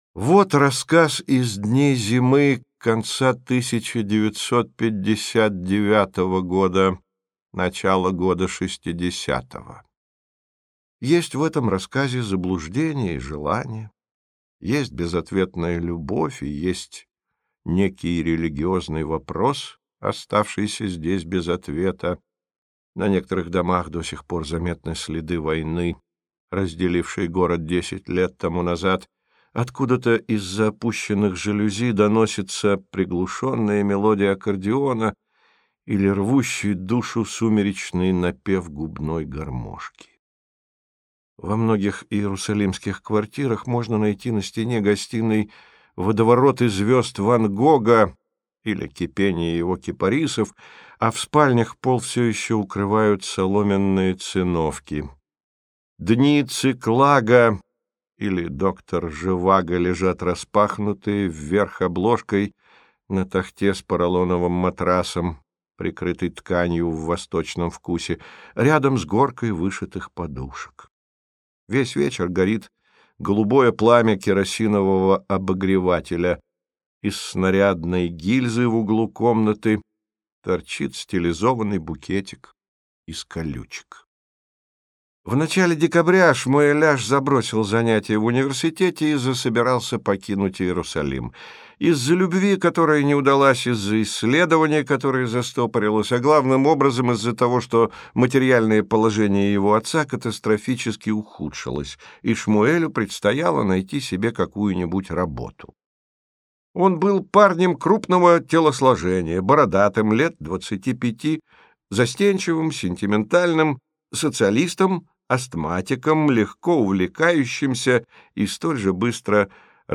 Аудиокнига Иуда - купить, скачать и слушать онлайн | КнигоПоиск
Прослушать фрагмент аудиокниги Иуда Амос Оз Произведений: 1 Скачать бесплатно книгу Скачать в MP3 Вы скачиваете фрагмент книги, предоставленный издательством